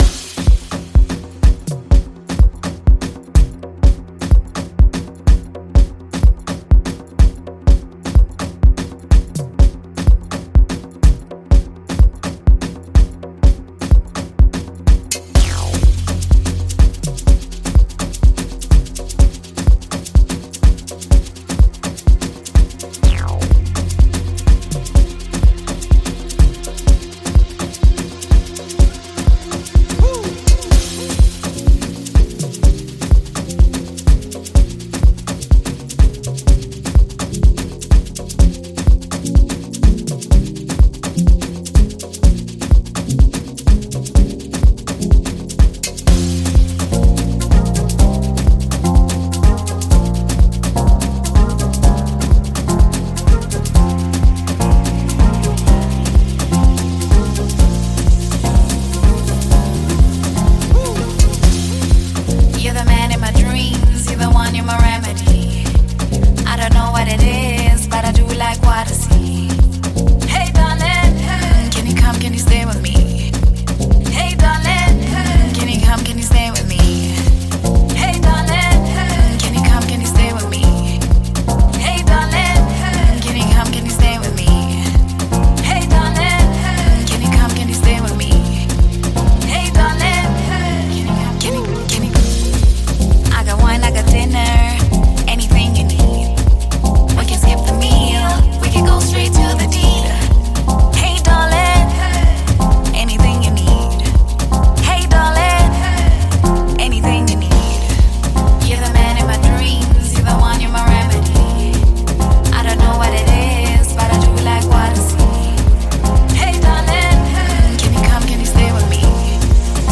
South African female vocalist
electrifying tune